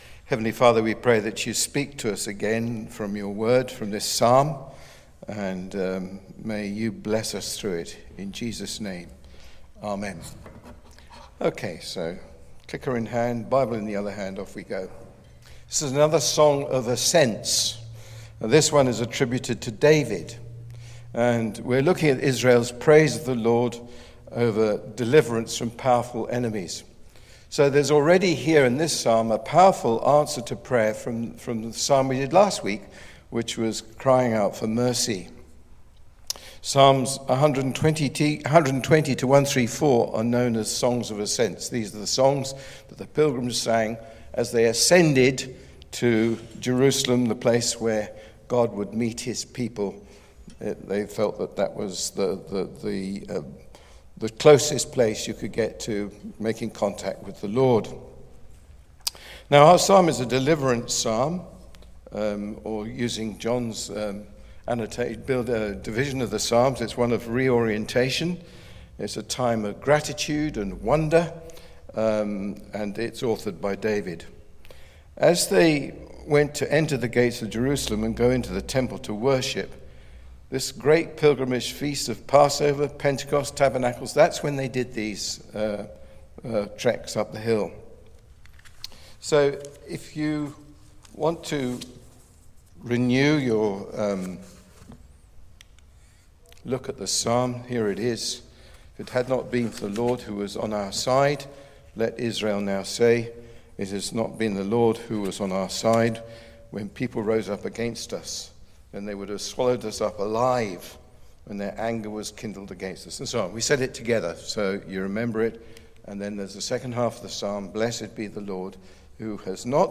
Listen to the sermon on Psalm 124 in our Psalms of Ascent series.